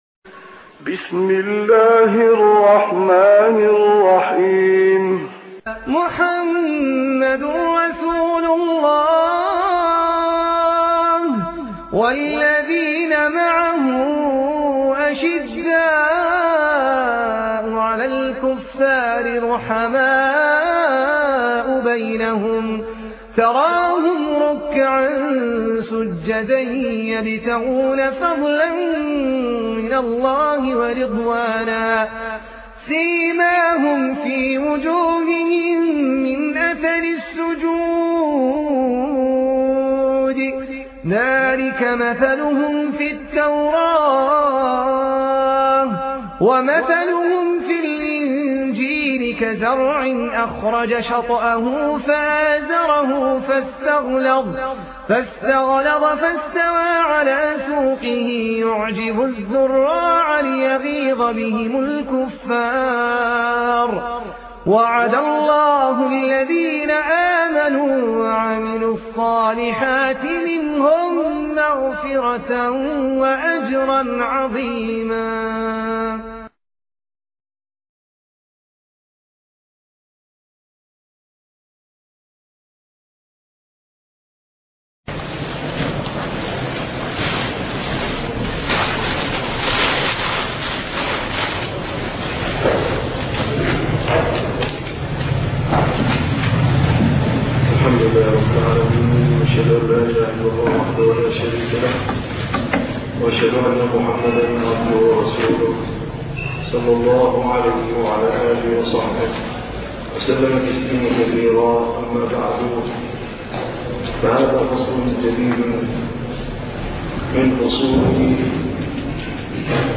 الدرس 16 ( شرح منار السبيل